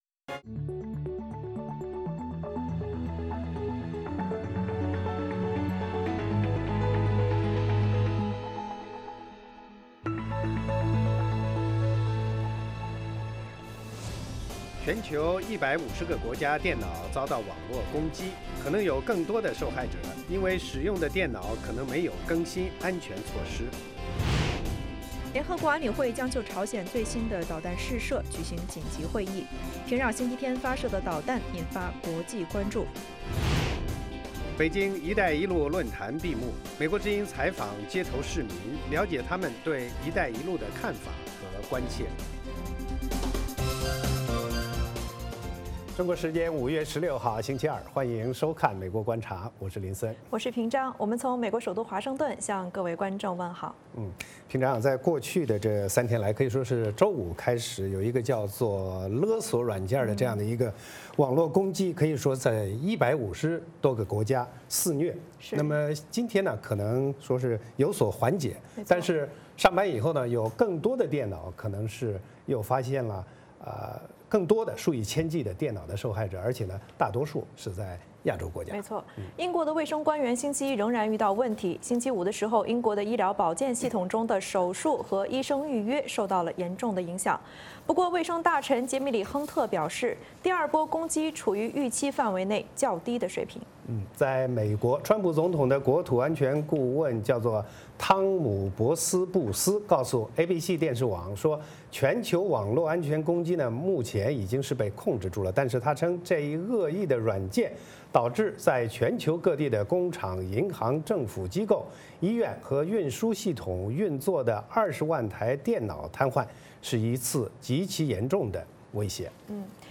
美国之音中文广播于北京时间晚上8－9点重播《VOA卫视》节目(电视、广播同步播出)。
“VOA卫视 美国观察”掌握美国最重要的消息，深入解读美国选举，政治，经济，外交，人文，美中关系等全方位话题。节目邀请重量级嘉宾参与讨论。